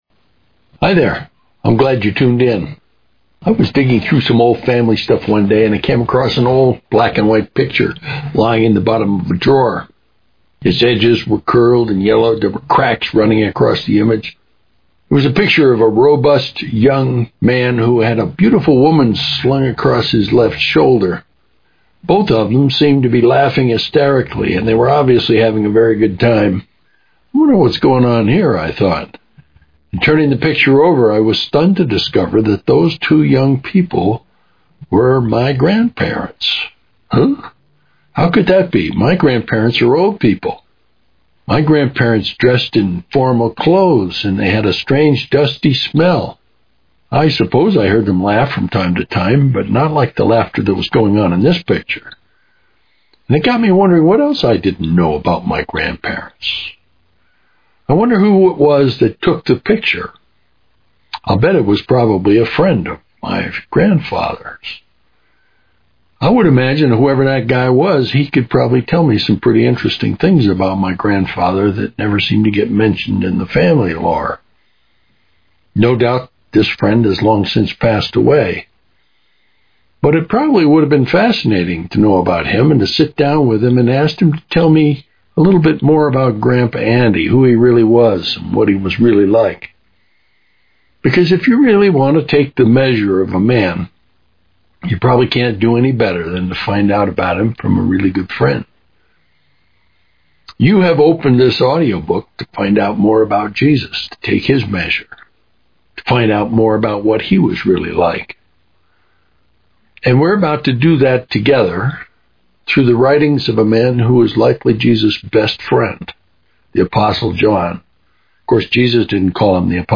This FREE audiobook download is a compilation of 67 messages from the Gospel of John.  To learn more about it, listen to this short audio introduction: